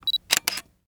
camera_shot.mp3